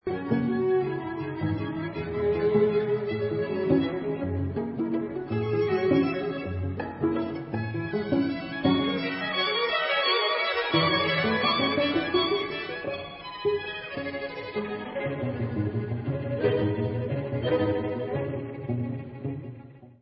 Assez vif. Tres rythmé